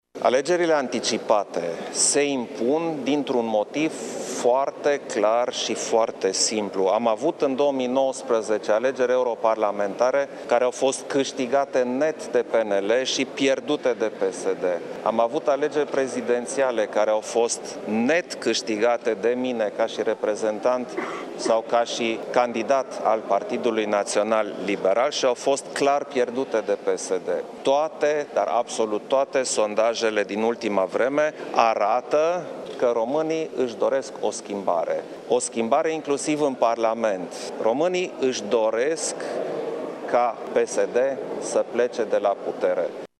De asemenea, într-o declaraţie de presă susţinută la Palatul Cotroceni, șeful statului a reafirmat că își dorește alegeri anticipate și a ținut să sublinieze: